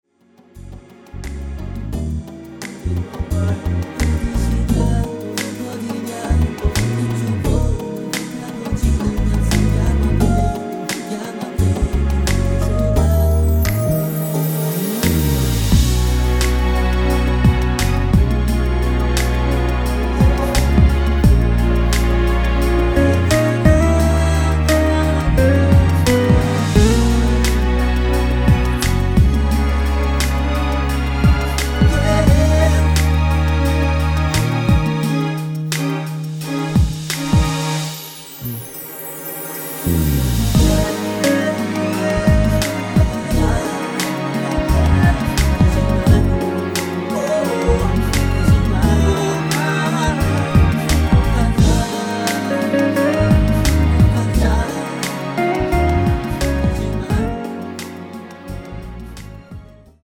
원키 코러스 포함된 MR 입니다.
F#
앞부분30초, 뒷부분30초씩 편집해서 올려 드리고 있습니다.